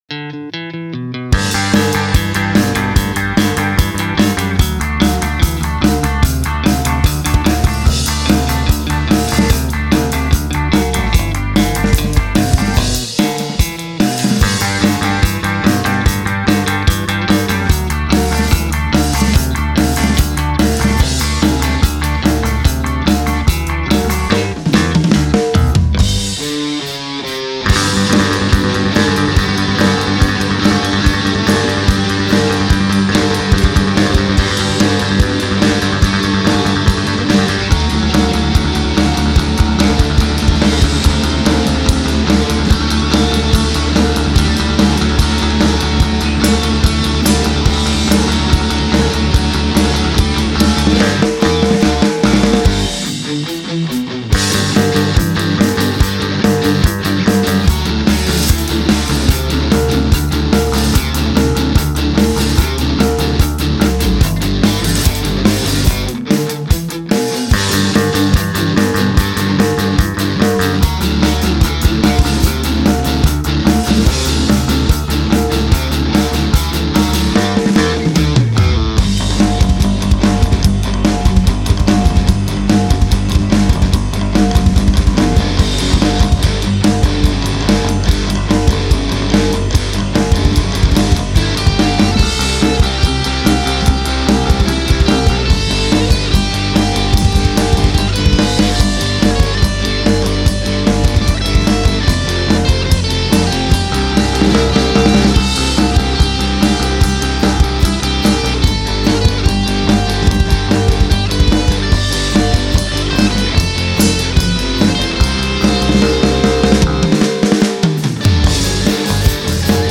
Basa = Schecter + GK (linka + mikrofon)